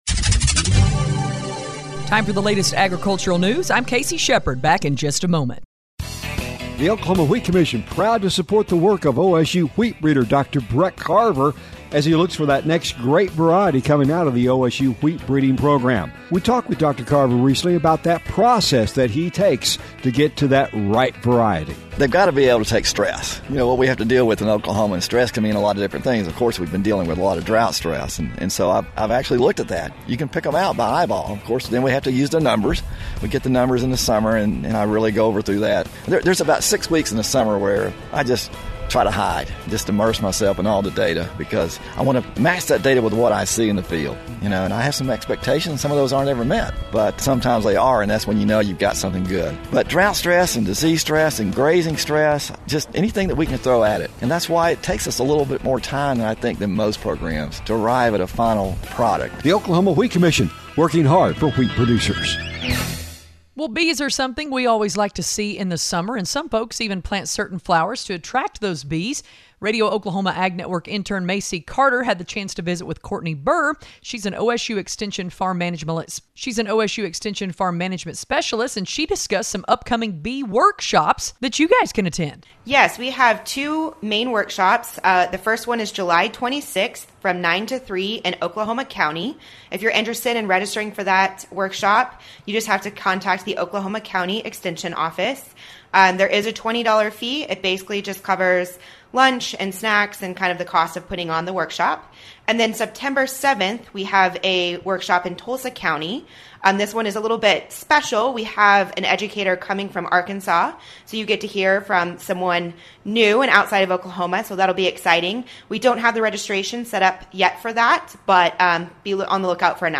We invite you to listen to us on great radio stations across the region on the Radio Oklahoma Ag Network weekdays-